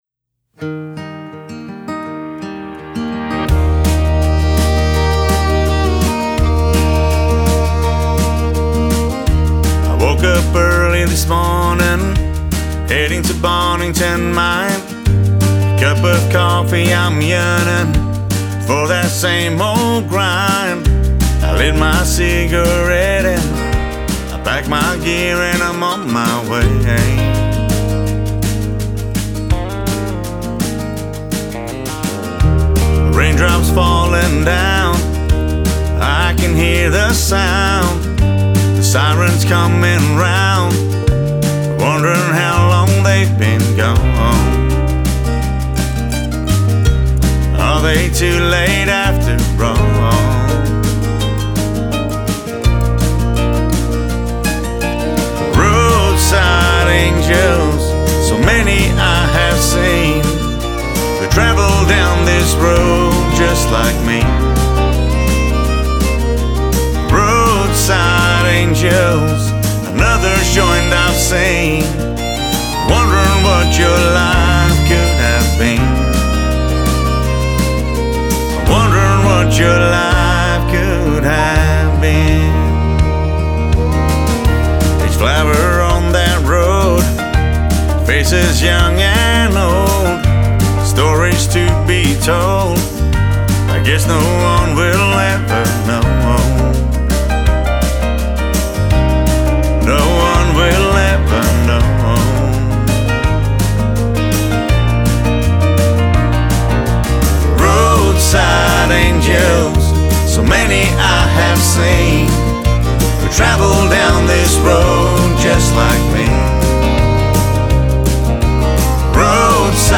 country singer-songwriter